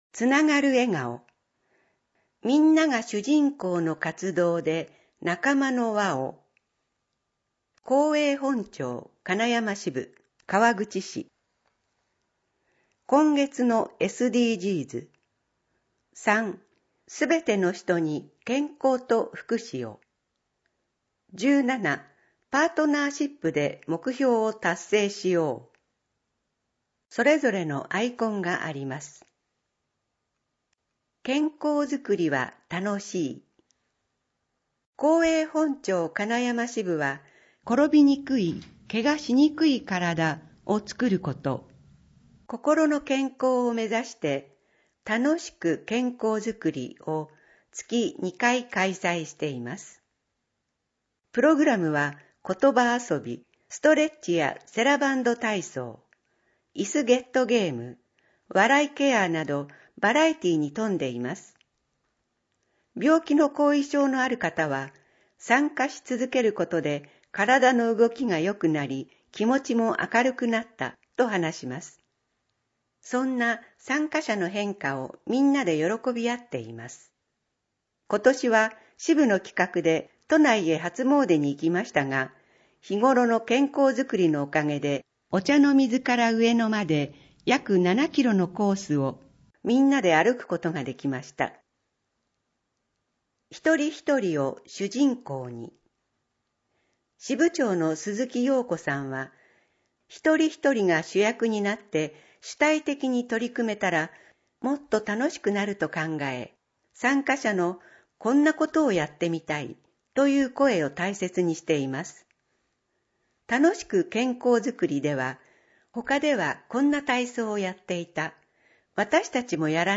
2023年5月号（デイジー録音版）